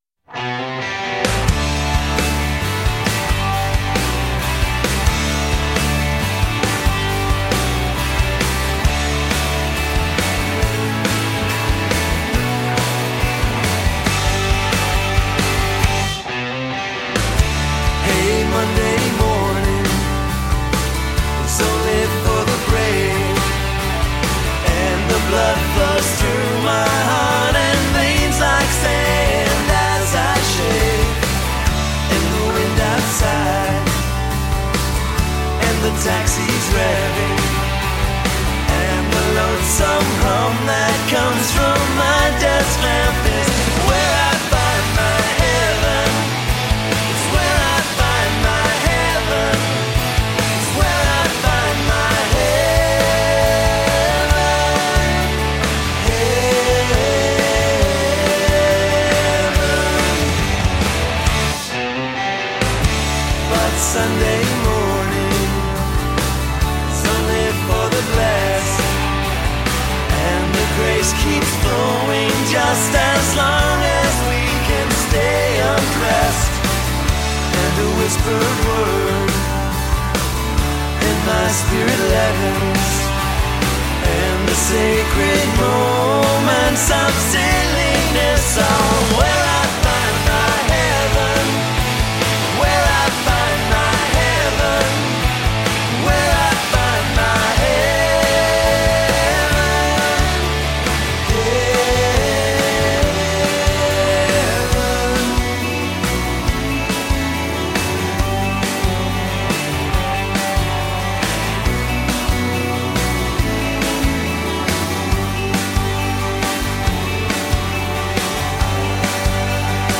Good power pop can make the hair on your arms stand up.